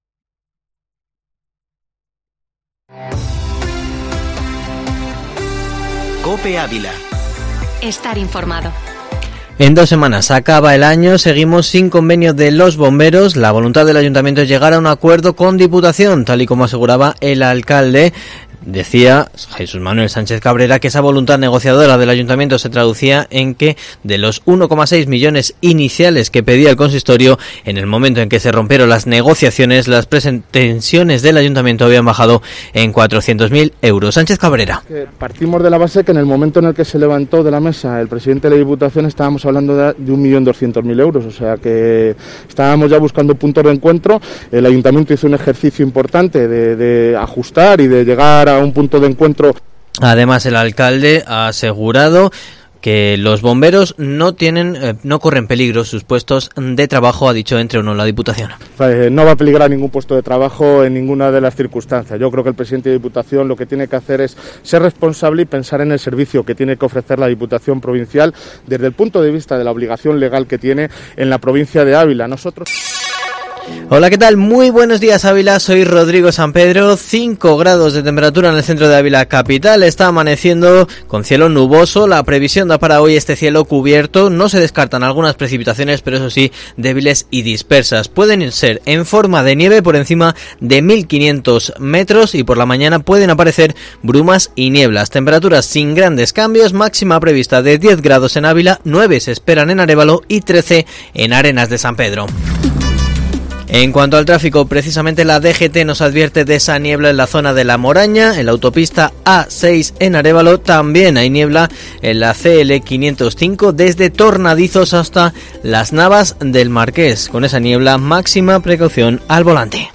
Informativo matinal Herrera en COPE Ávila 17/12/2020